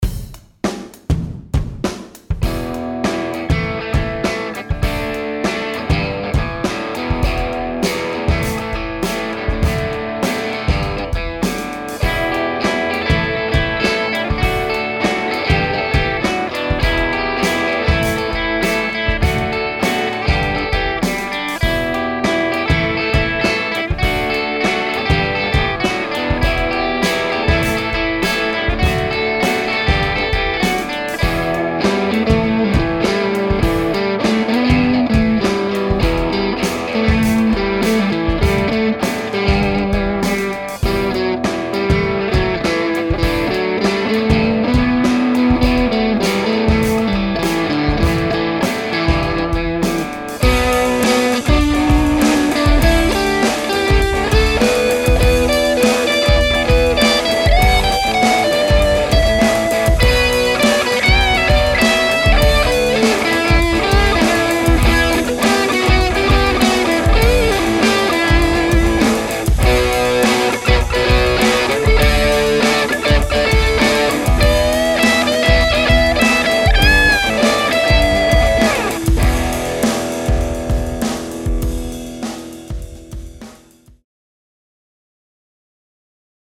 Here’s a little tune I put together to showcase the Blues Driver. All the guitar sounds use the pedal with various tweaks to the tone and gain settings. The only other equipment was a Blackstar HT-5R amp set to clean and a Yamaha Pacifica guitar. I definitely made use of the coil splitting feature on the Yamaha’s bridge humbucker using the single coil for the lighter rhythm sounds and switching to the full humbucker for the heavier lead sections. There’s a bit of neck pickup soloing in there too.
BossBluesDriverOverdriveDemo.mp3